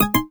volumedown.wav